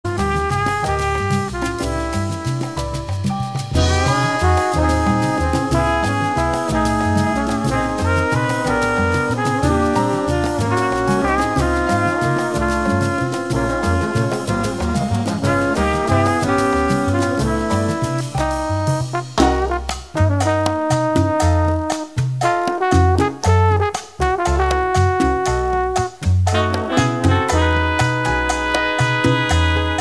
sassofono tenore
timbales